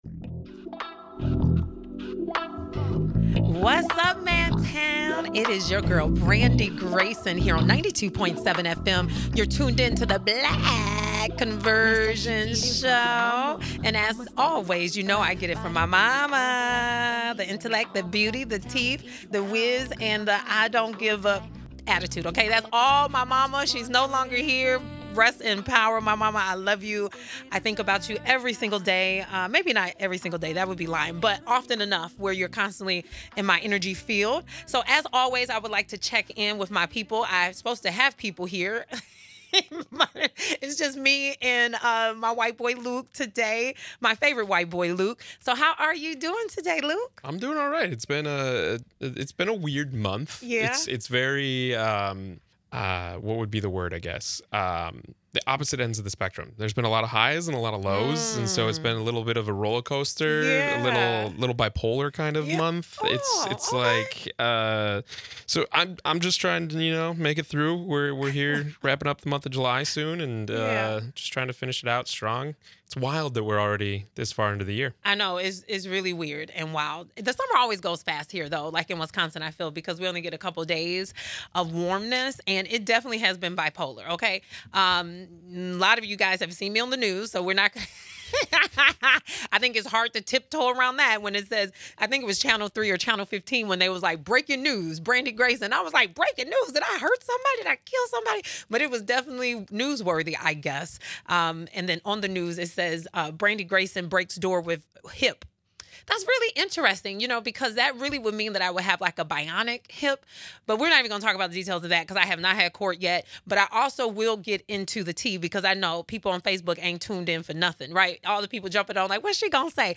Discussions, guests, and interviews will focus on vulnerable populations--specifically Black families. We cover controversial topics, from religion to race and from relationships to policies to legislation.